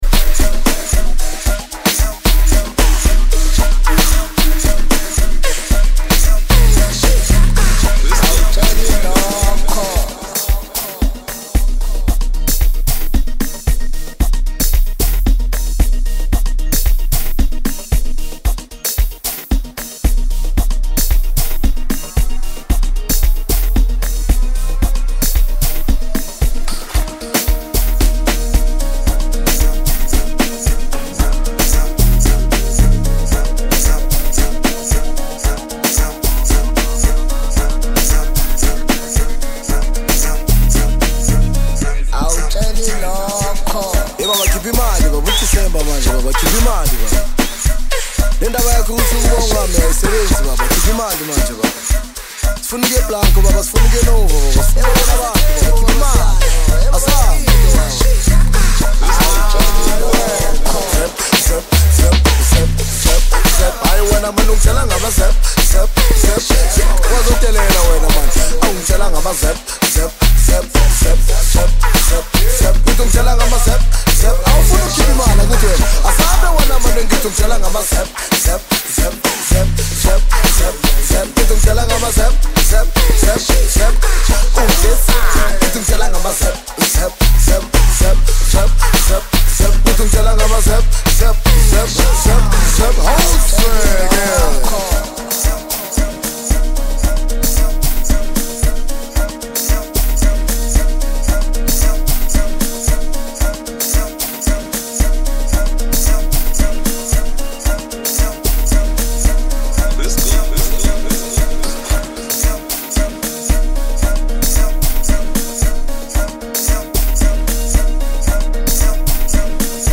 Home » Amapiano » DJ Mix